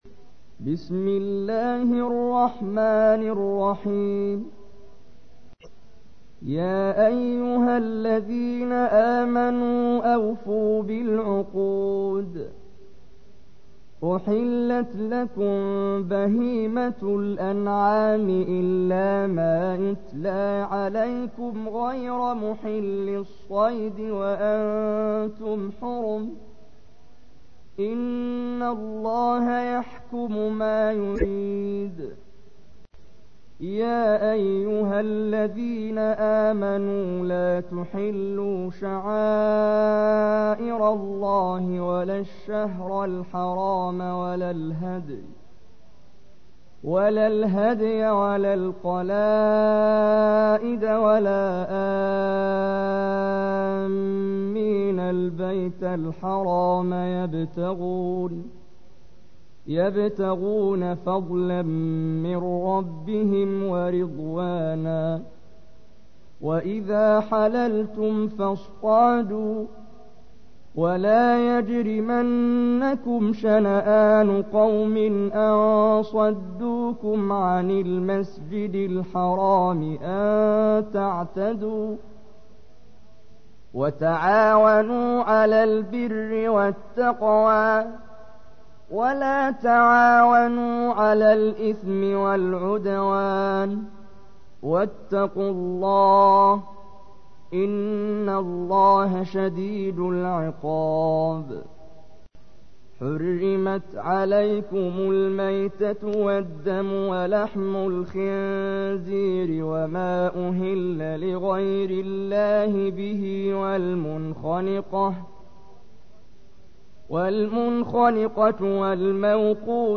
تحميل : 5. سورة المائدة / القارئ محمد جبريل / القرآن الكريم / موقع يا حسين